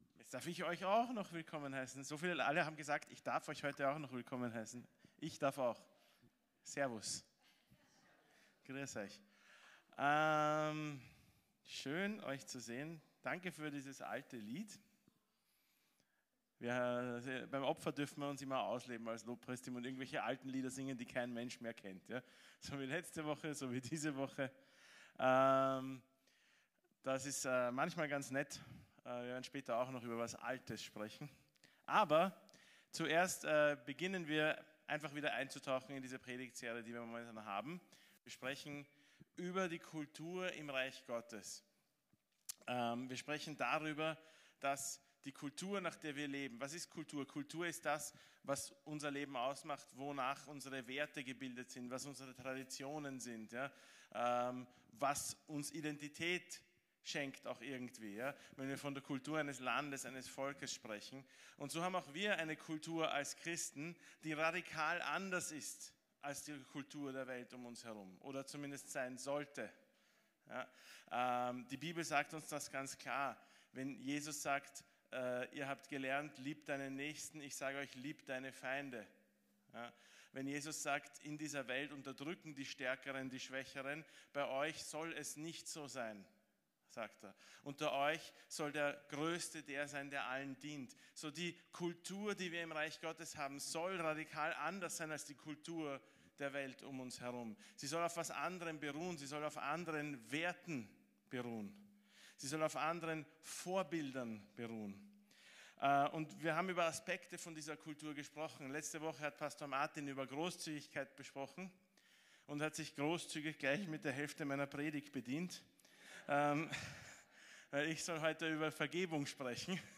KÖNIGREICH GOTTES KULTUR ~ VCC JesusZentrum Gottesdienste (audio) Podcast